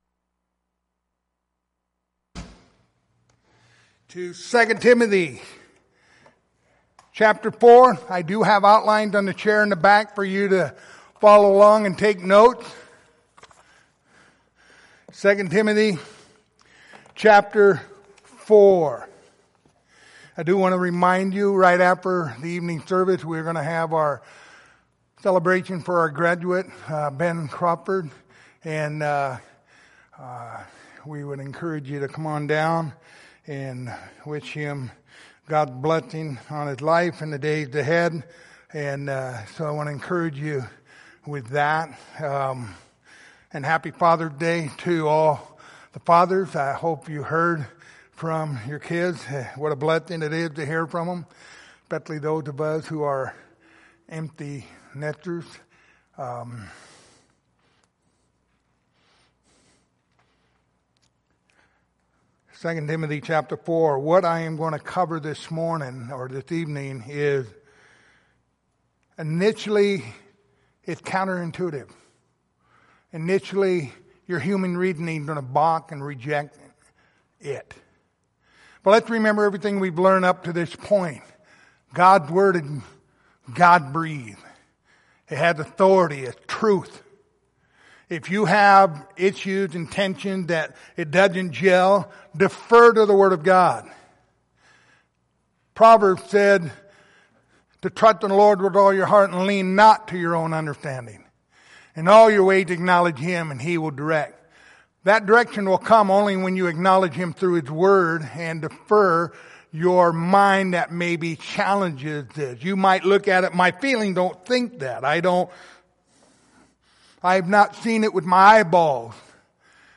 Passage: 2 Timothy 4:3-4 Service Type: Sunday Evening